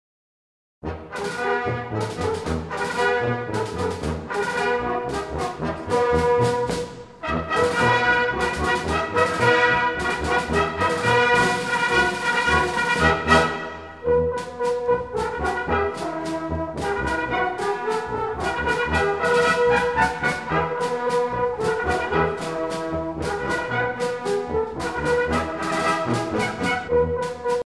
danse : valse
circonstance : militaire
Pièce musicale éditée